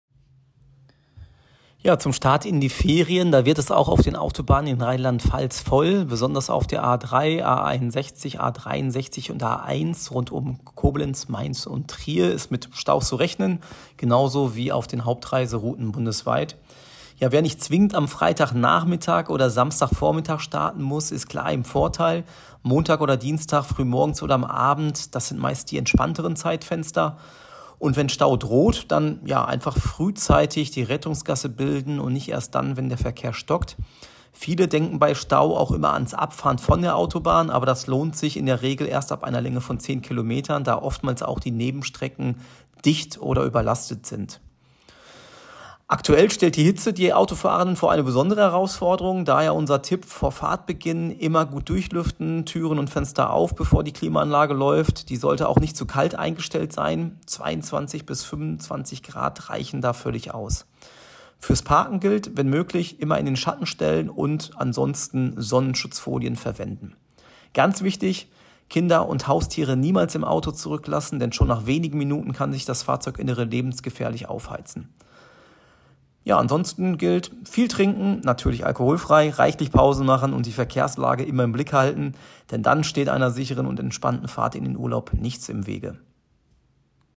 O-Ton